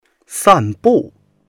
san4bu4.mp3